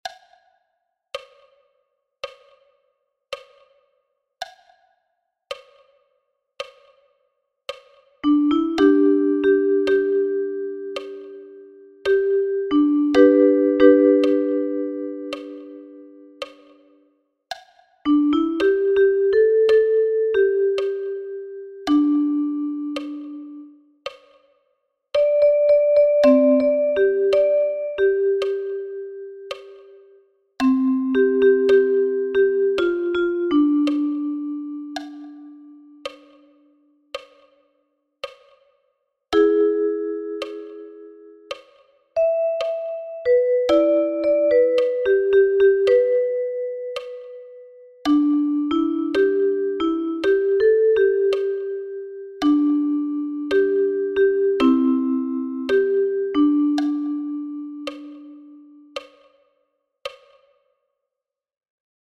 für die Ukulele